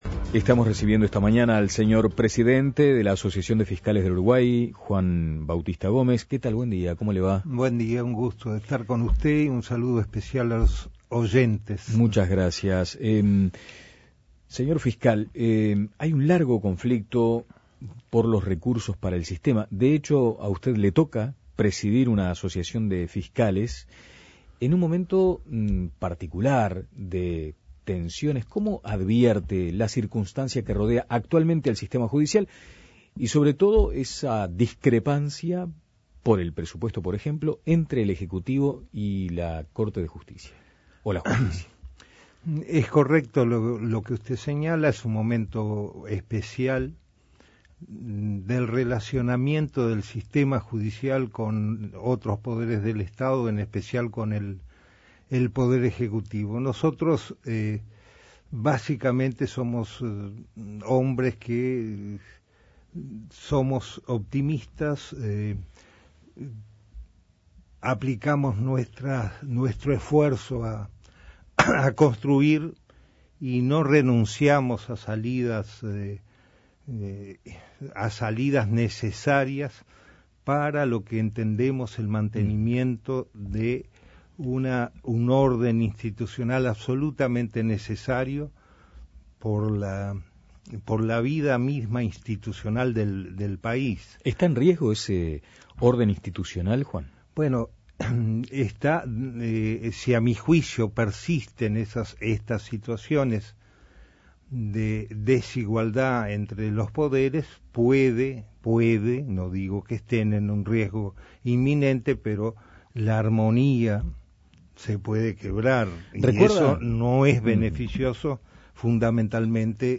Entrevista a Juan Bautista Gómez